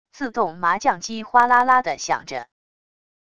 自动麻将机哗啦啦地响着wav音频